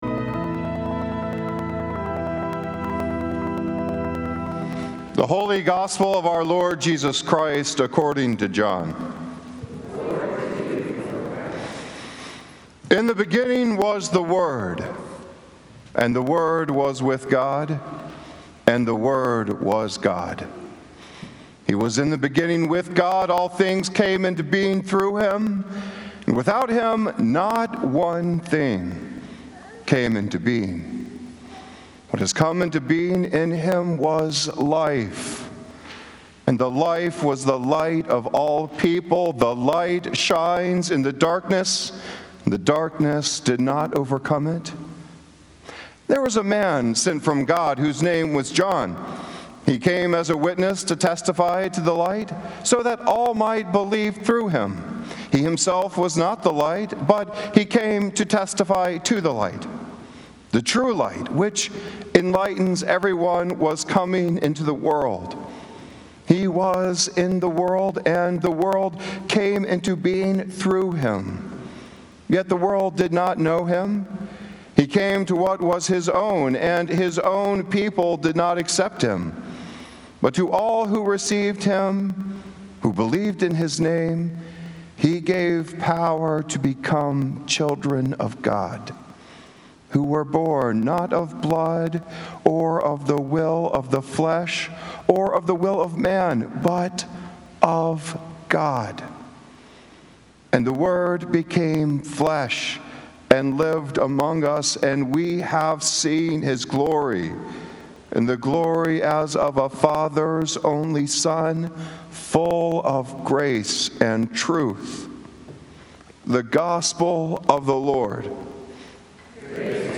Christmas Day Sermon
Sermons from St. Columba's in Washington, D.C.